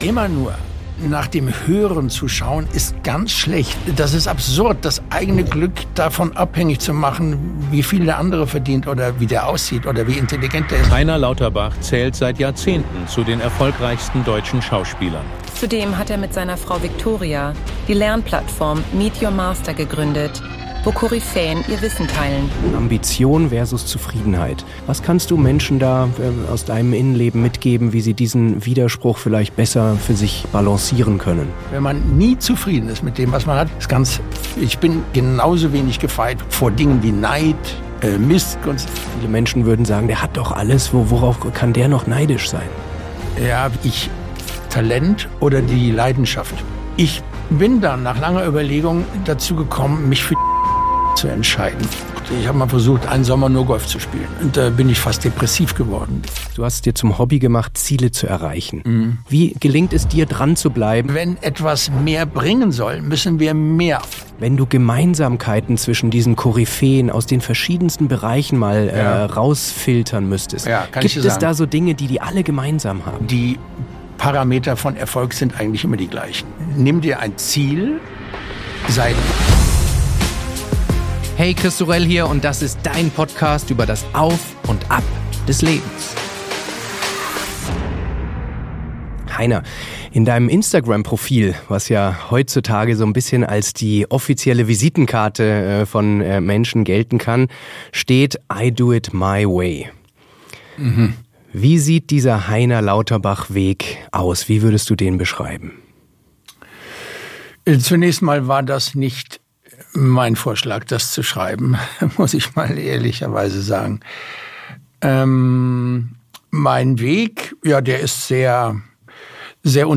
Welche genau das sind und wie wir alle sie in unserem Leben umsetzen können, teilt der Schauspieler in diesem SEHR inspirierenden Gespräch